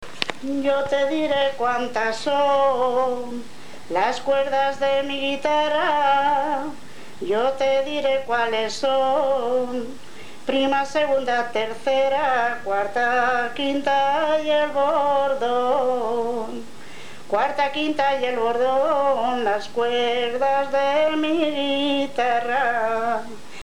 Jotas y canciones de ronda